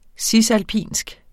Udtale [ ˈsisalˌpiˀnsg ]